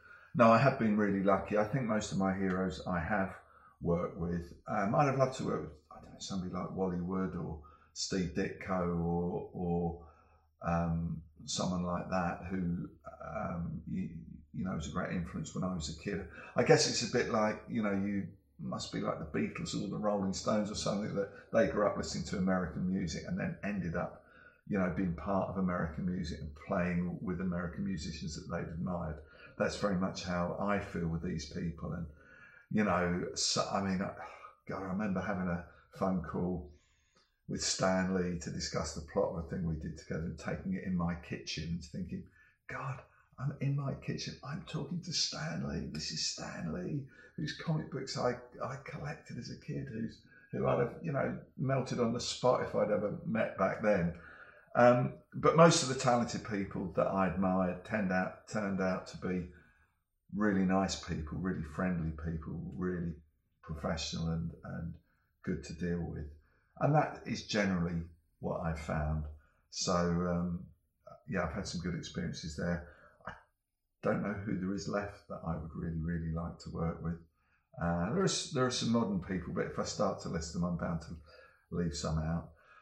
Dave Gibbons interview: Is there anyone you'd like to have worked with?